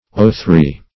O3 - definition of O3 - synonyms, pronunciation, spelling from Free Dictionary